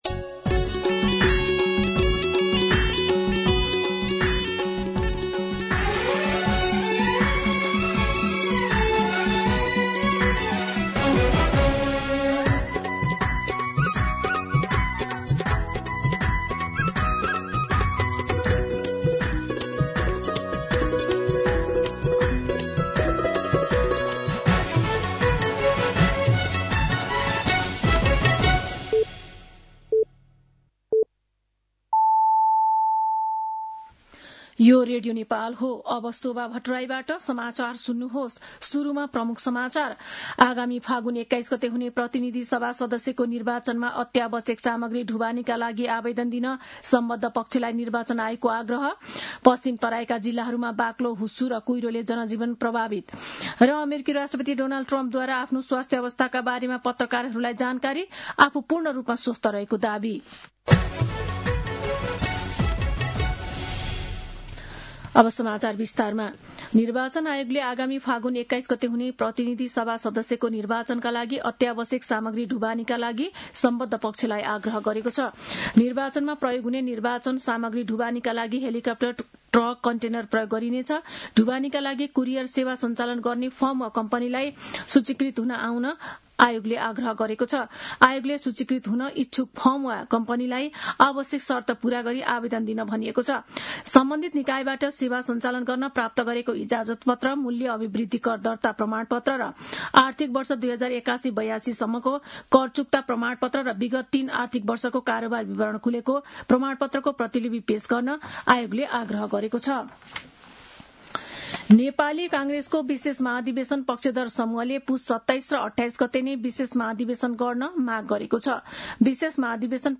दिउँसो ३ बजेको नेपाली समाचार : १८ पुष , २०८२